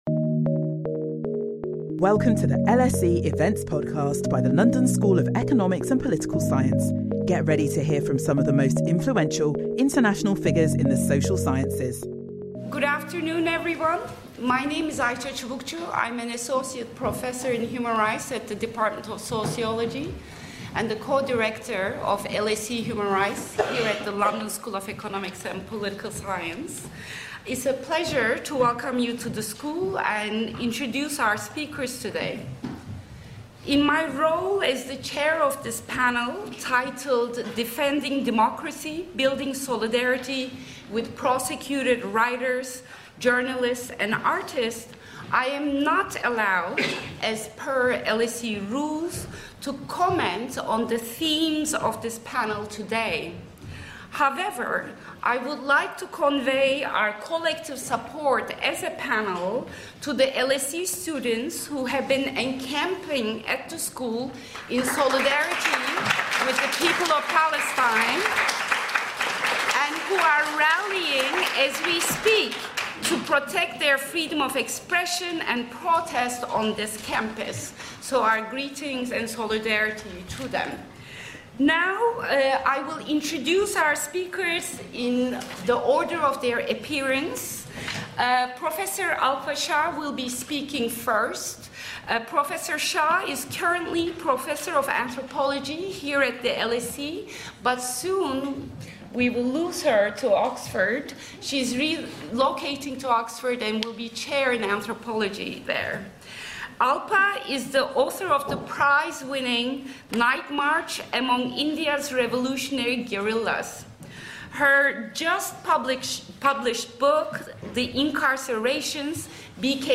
Our panel examine the persecution of writers, academics, journalists and artists across the globe.